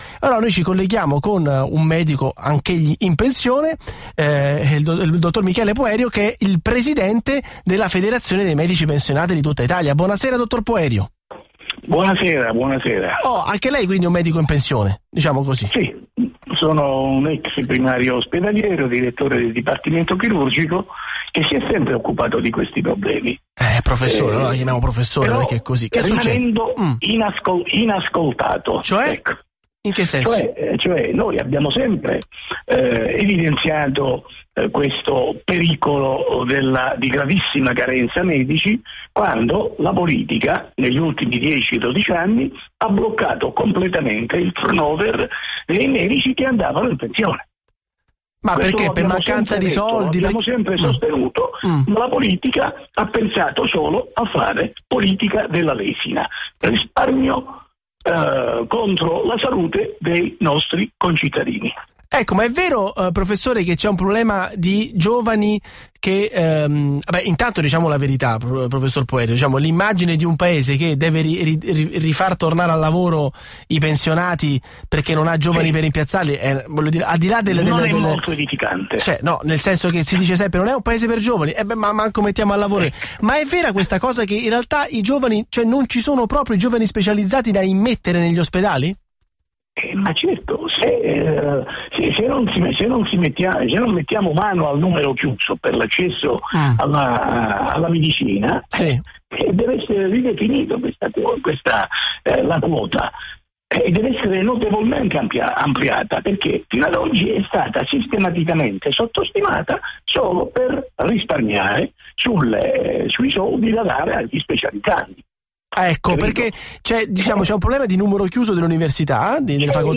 Intervista a Radio Capital